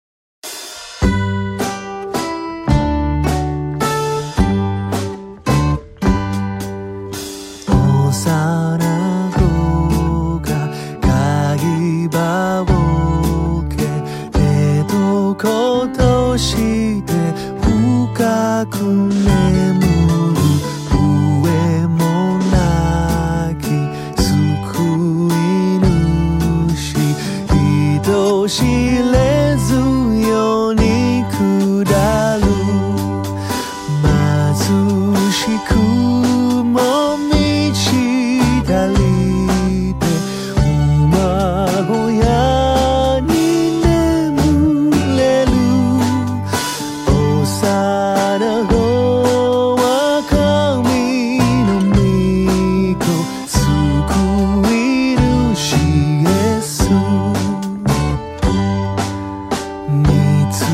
女声合唱
混声合唱